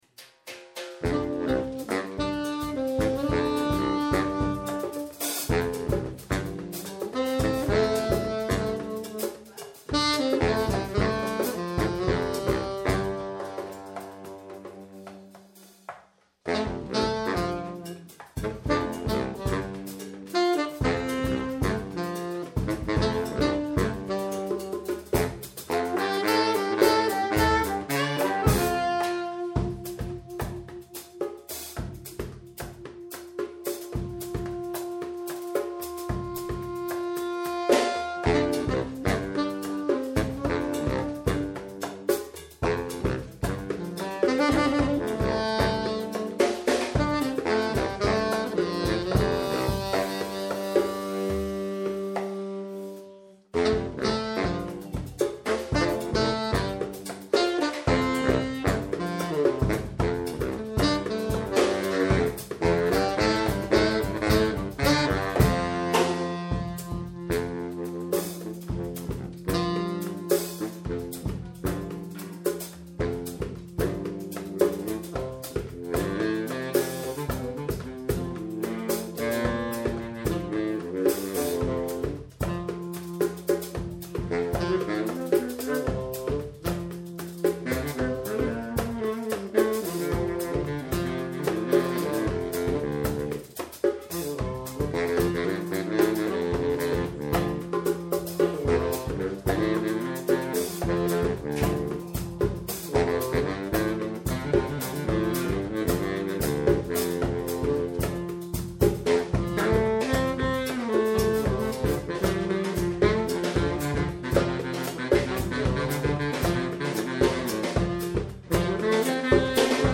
Live-Mitschnitt vom 5. April 2000
reeds, flute
percussion
djembe, cora, voc